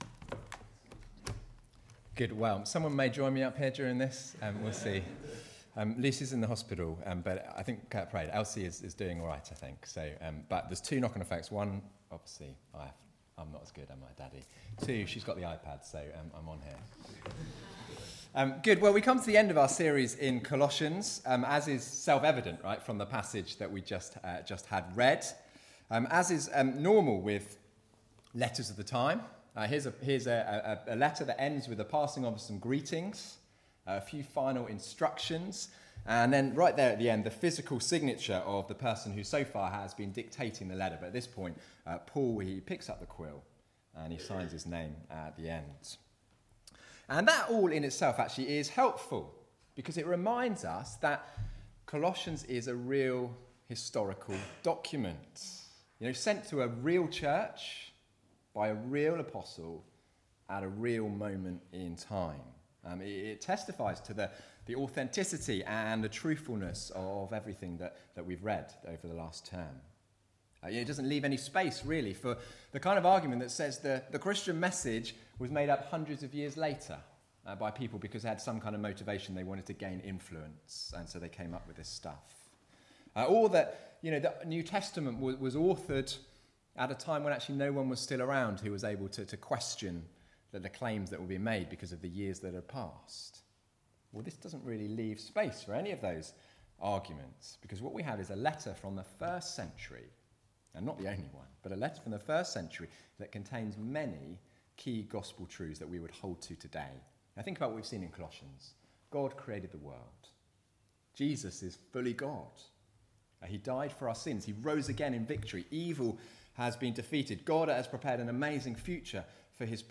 Passage: Colossians 4:7-18 Service Type: Weekly Service at 4pm